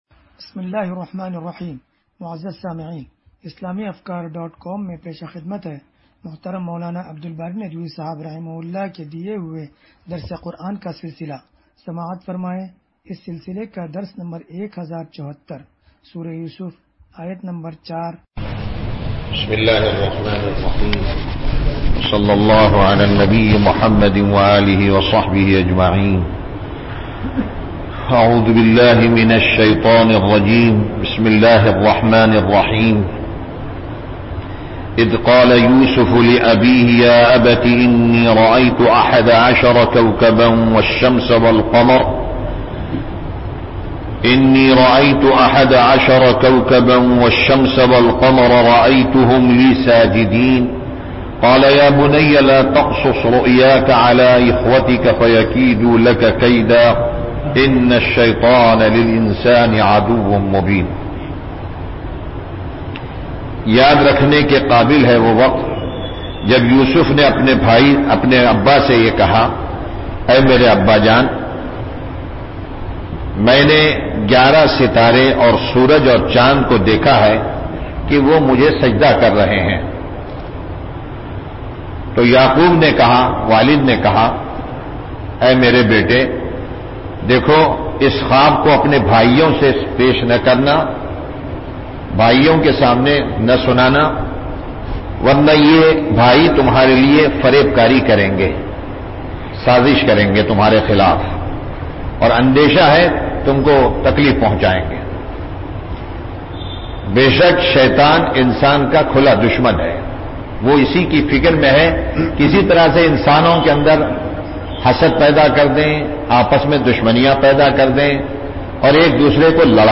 درس قرآن نمبر 1074